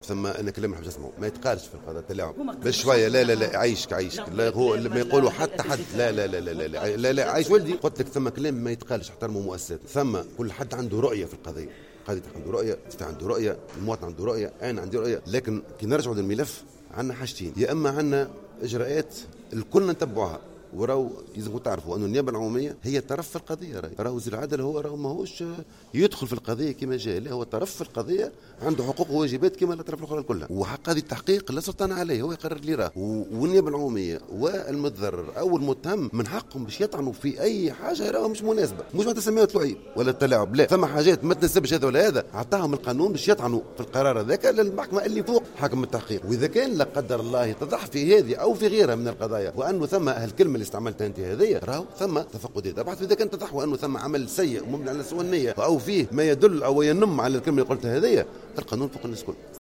Le ministre a affirmé lors d'une conférence de presse en marge de la séance d'audition tenue ce mardi à l'ARP autour des affaires d'assassinat de Chokri Belaïd et Mohamed Brahmi, que le juge d'instruction est indépendant. Ecoutez le ministre omar mansour t√©l√©charger partager sur